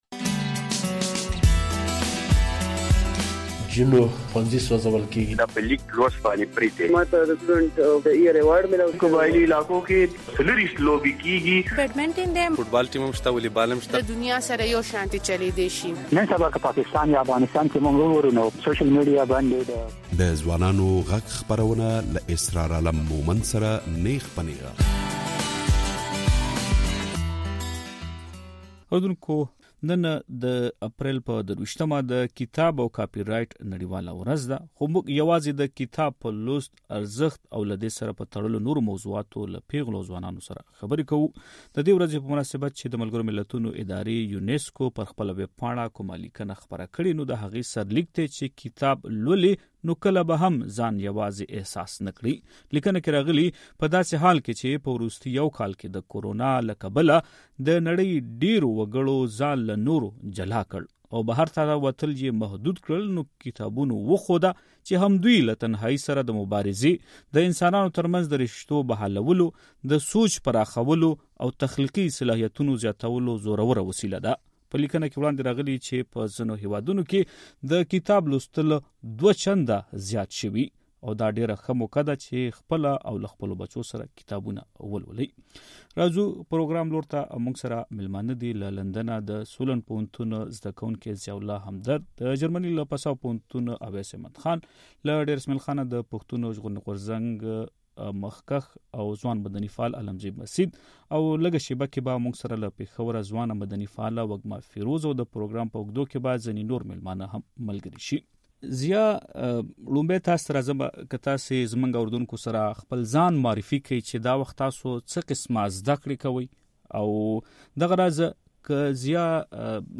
خو موږ په ځوانانو غږ خپرونه کې یوازې د کتاب پر لوست، ارزښت او له دې سره په تړلو نورو موضوعاتو په اروپا او خیبر پښتونخوا کې له ځوانانو سره خبرې کړي. د خپرونې برخوال وايي، ځوانان اکثر د کتاب پر ځای په ټولنیزو شبکو کې وخت تېروي چې د هغوی د تاوان سبب کېدای شي.